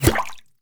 bullet_impact_water_04.wav